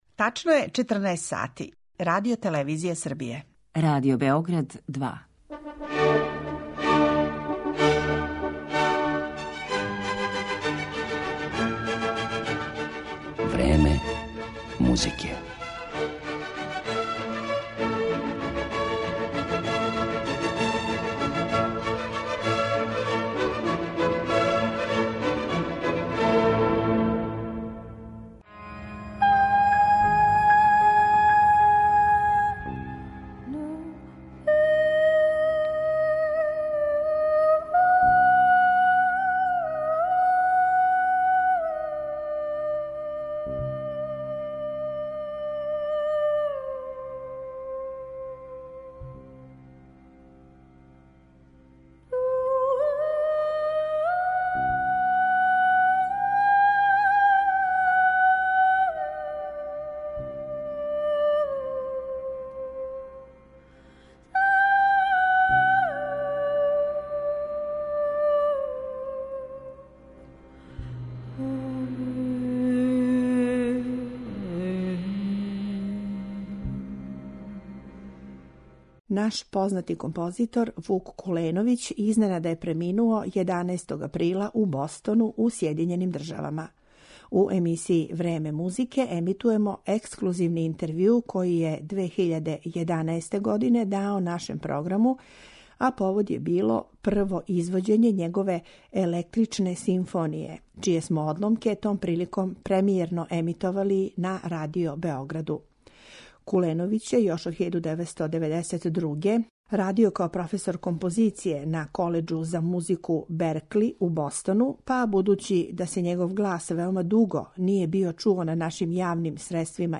одломцима интервјуа